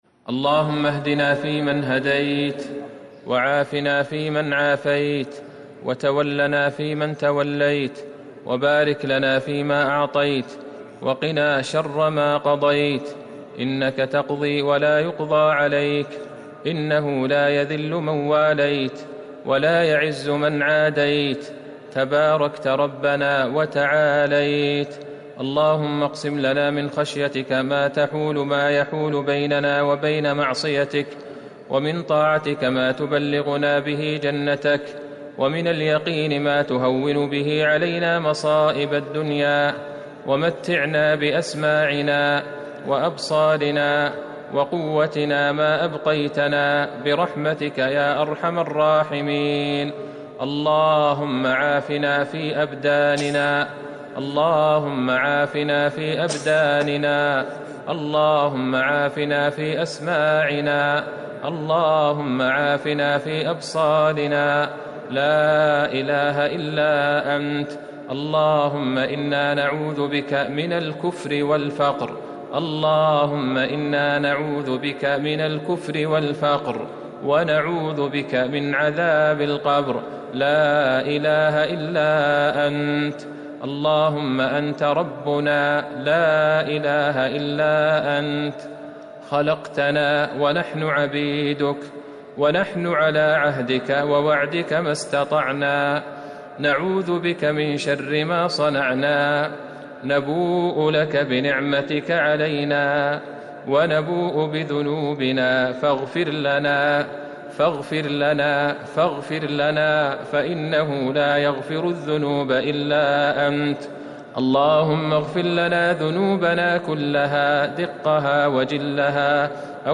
دعاء القنوت ليلة 22 رمضان 1441هـ > تراويح الحرم النبوي عام 1441 🕌 > التراويح - تلاوات الحرمين